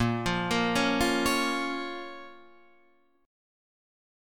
Bbm chord